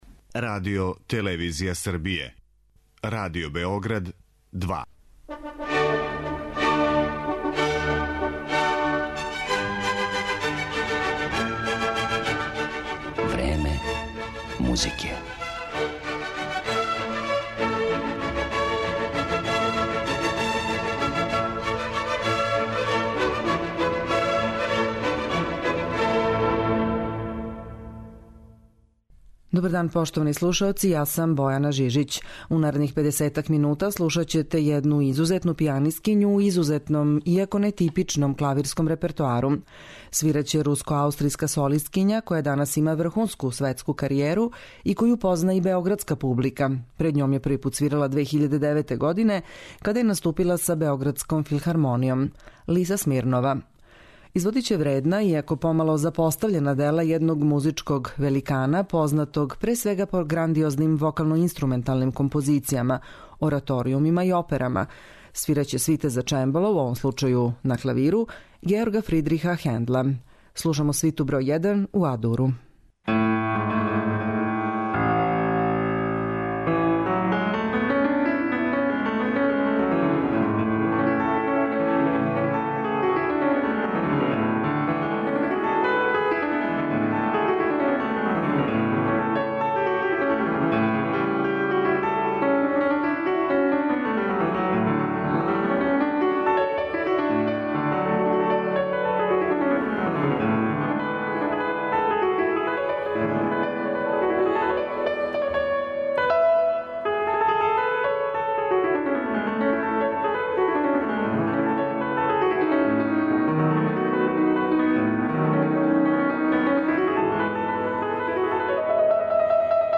клавирских интерпретација свита за чембало